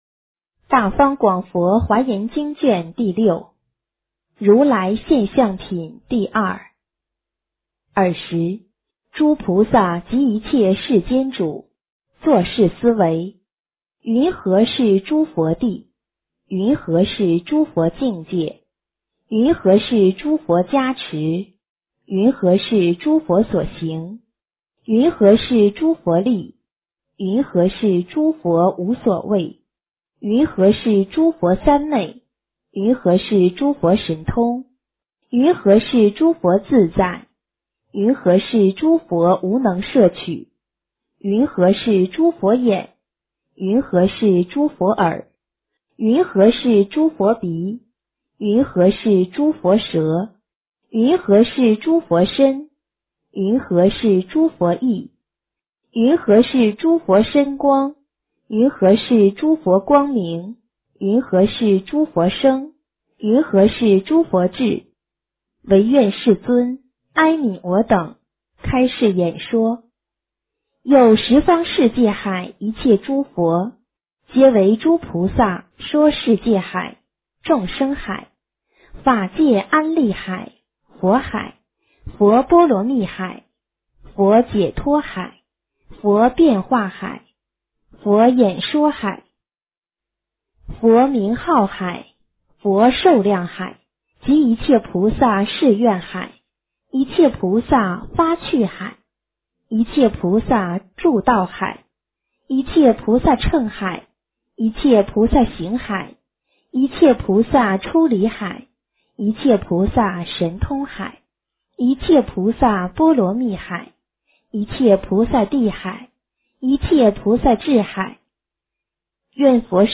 华严经06 - 诵经 - 云佛论坛